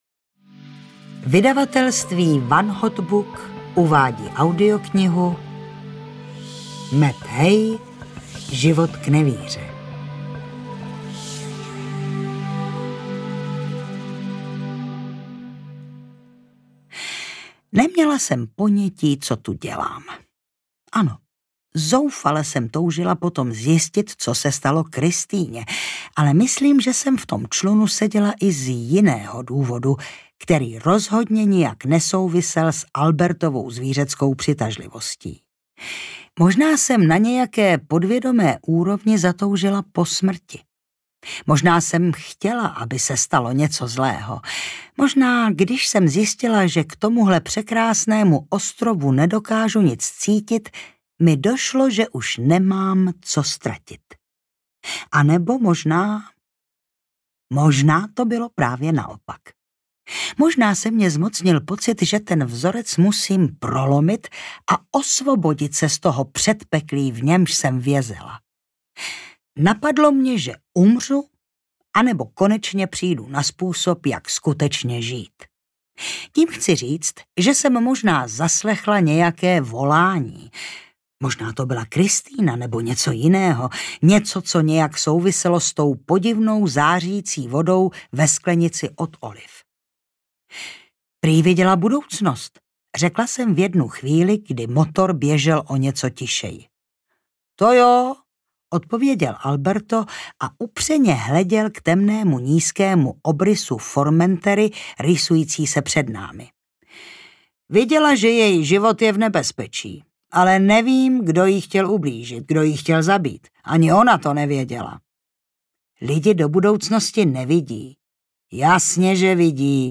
Život k nevíře audiokniha
Ukázka z knihy
zivot-k-nevire-audiokniha